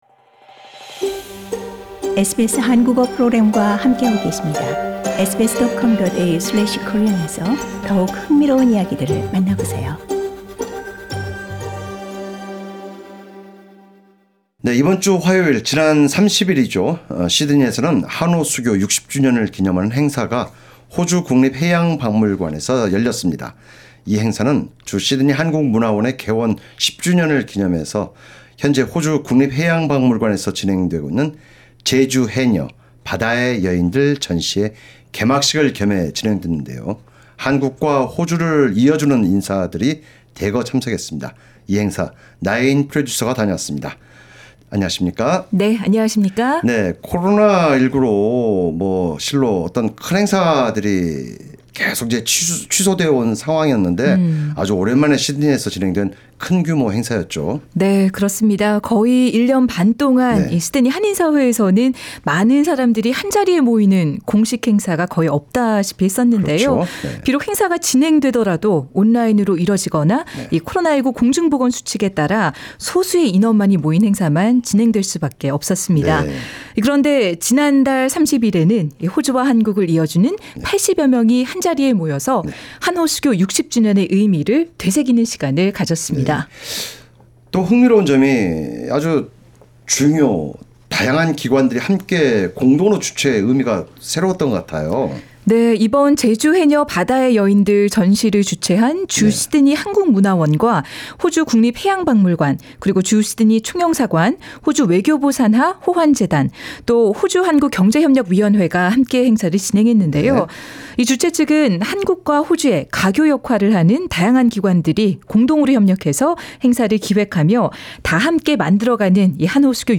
SBS 한국어 프로그램